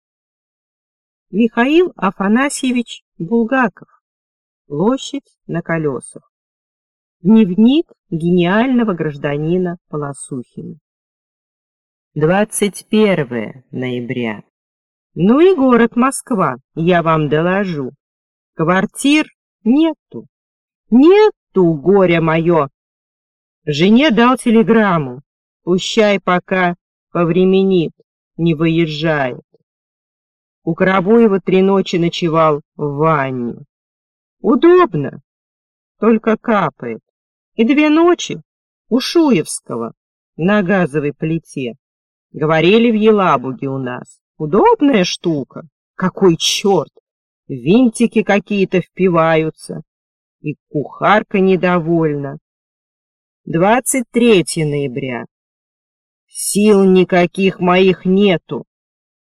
Аудиокнига Площадь на колесах | Библиотека аудиокниг